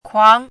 chinese-voice - 汉字语音库
kuang2.mp3